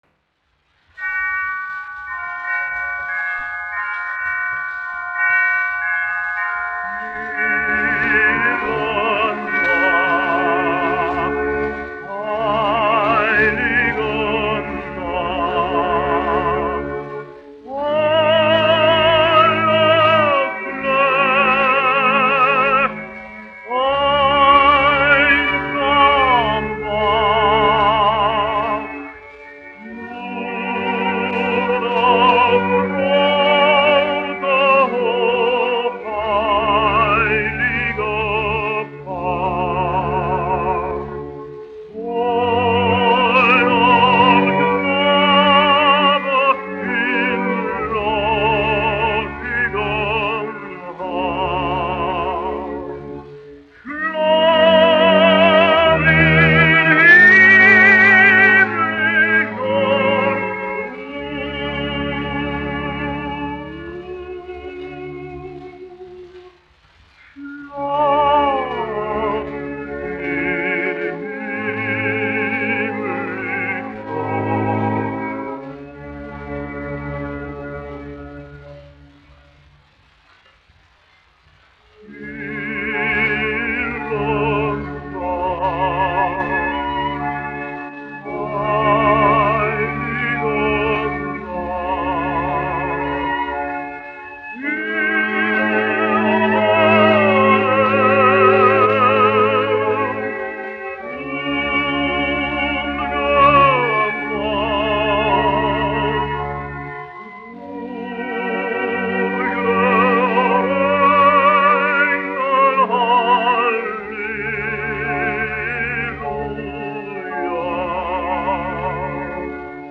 1 skpl. : analogs, 78 apgr/min, mono ; 25 cm
Ziemassvētku mūzika
Skaņuplate